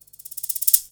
TI124PERC1-R.wav